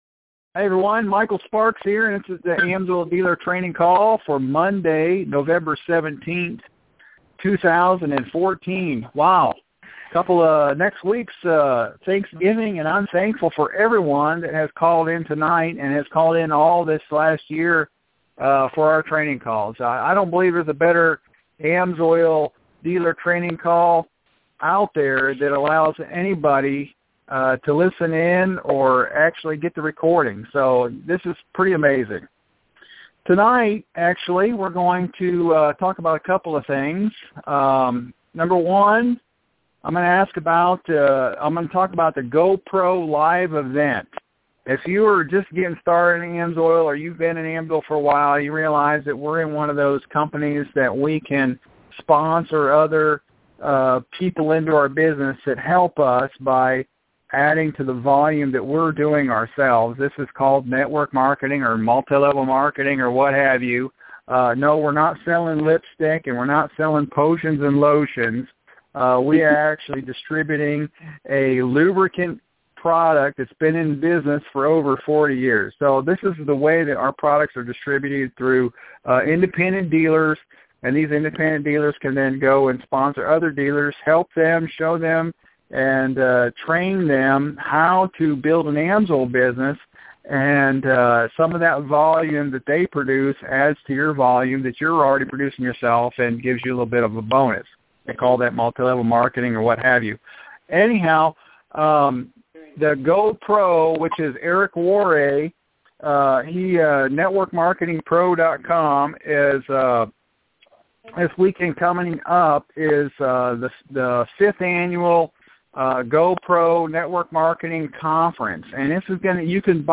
Sparks Team AMSOIL Dealer Training Call | November 17th, 2014